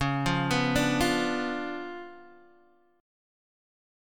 C#7b9 chord